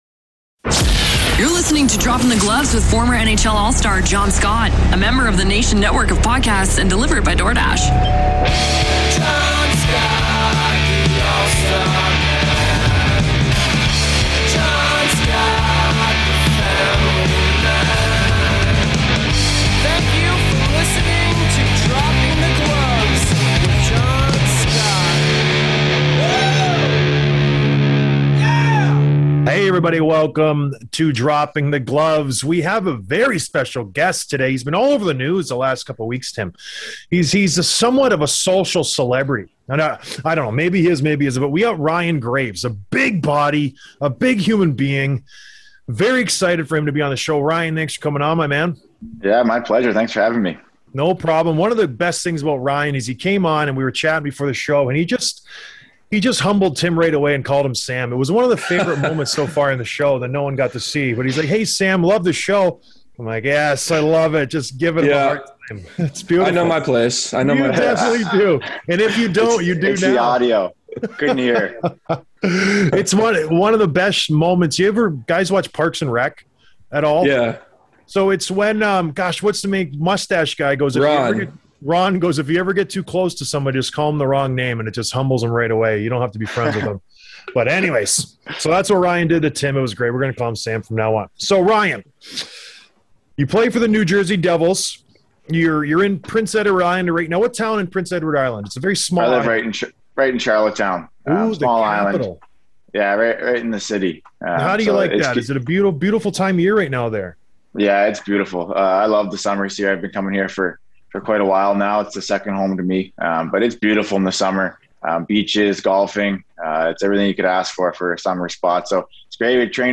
Interview with Ryan Graves, New Jersey Devils